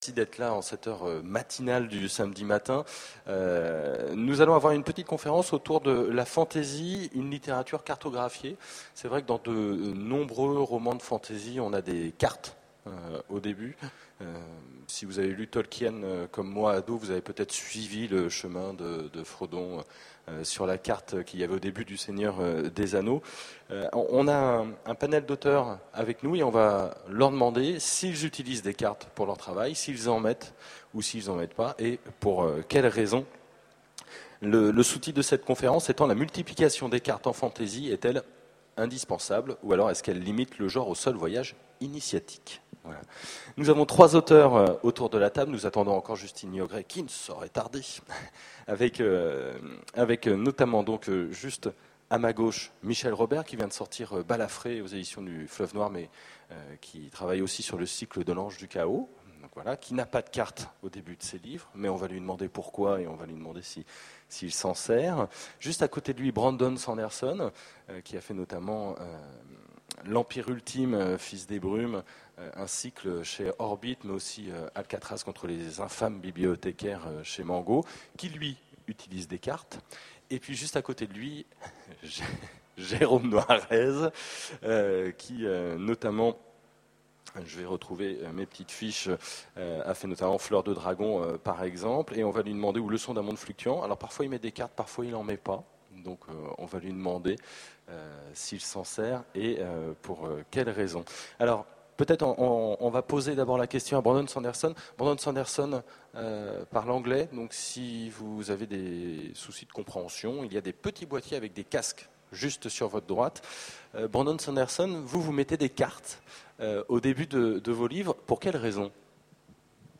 Voici l'enregistrement de la conférence " Fantasy, une littérature cartographiée ? " aux Utopiales 2010. La multiplication des cartes en fantasy est-elle indispensable ? Limite-t-elle le genre au seul voyage initiatique ?